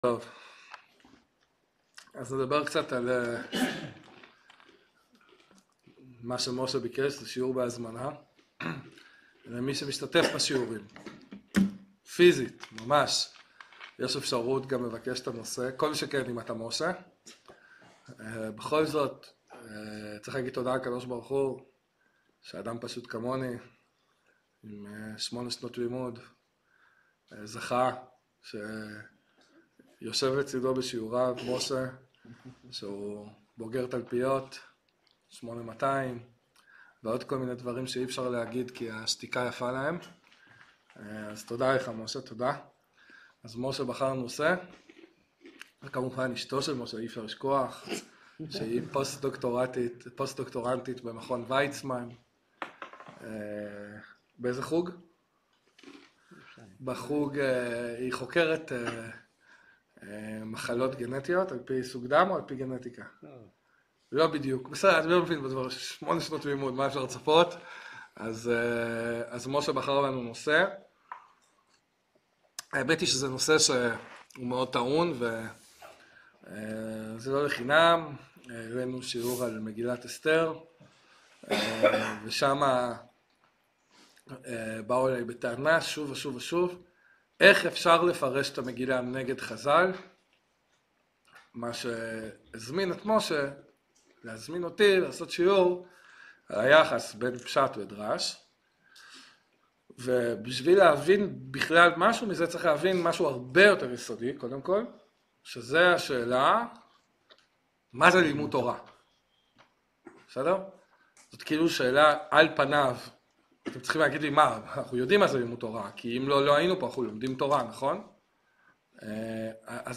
שיעור עמוק ויסודי המבאר את הדרך הנכונה ללימוד תורה, המשמעות שלה וההבדלים ההכרחיים בין פשט לדרש ובין פרשנות לדרשנות.